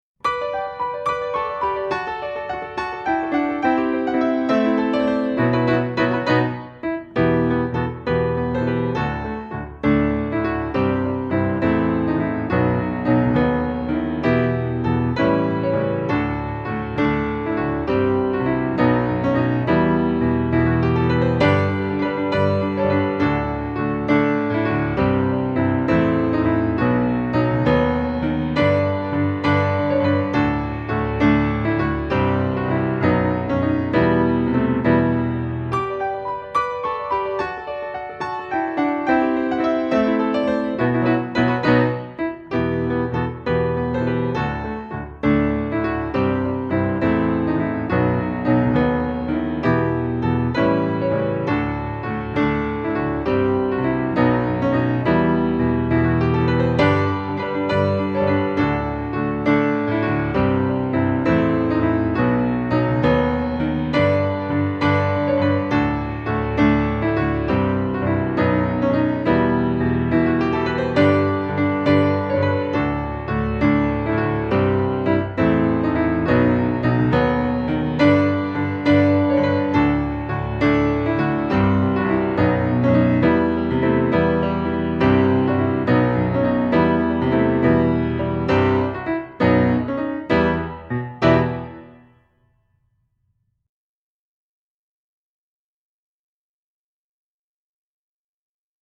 Christmas, Traditional, Popular carol
DIGITAL SHEET MUSIC - VIOLIN SOLO & DUET